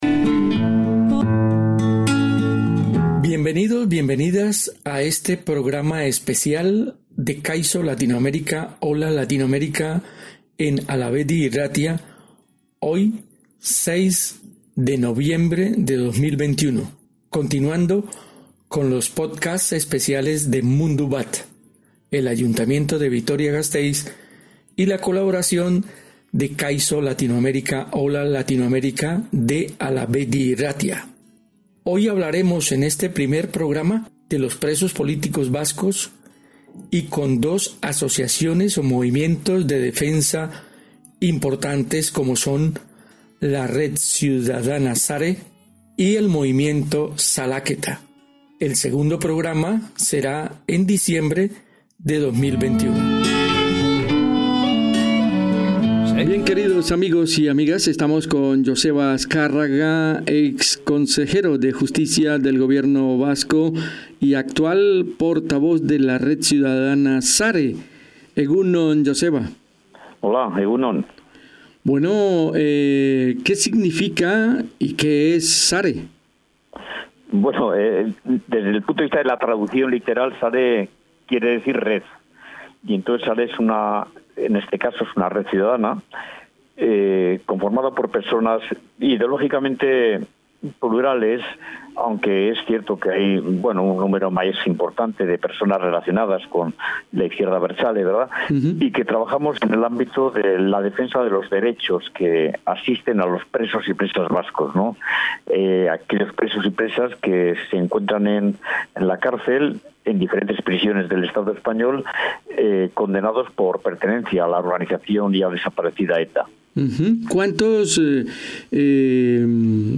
Entrevistamos a Joseba Azkarraga coordinador de la Red SARE en el País Vasco y con quien valoramos cómo ha quedado la política penitenciaria con la transferencia de prisiones a la CAV, si puede haber otra política penitenciaria y el balance que se hace a los 10 años del desarme de ETA.